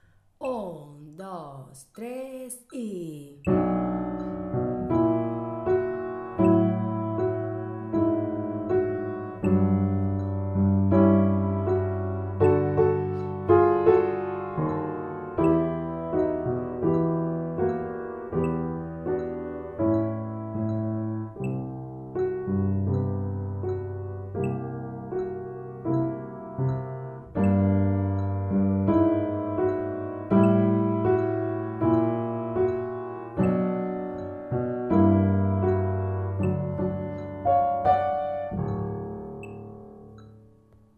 De cada una de ellas hay un audio con la música del piano que va a acompañaros y otro audio con la canción tocada por el violín.